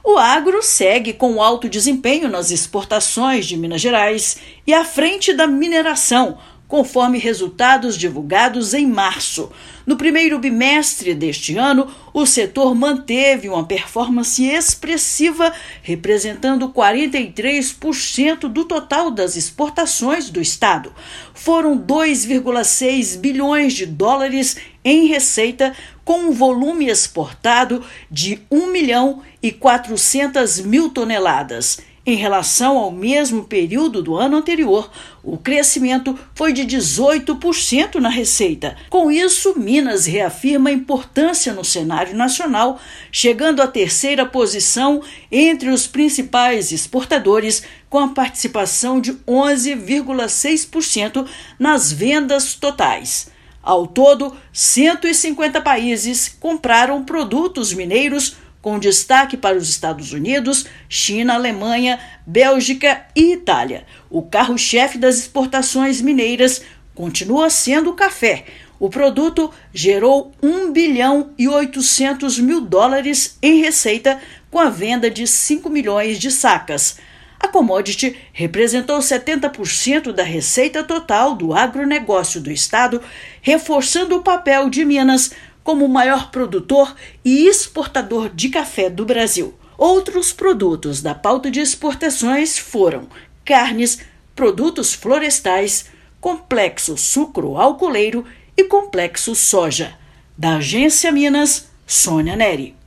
Resultado do primeiro bimestre de 2025 é o melhor período da série histórica. Ouça matéria de rádio.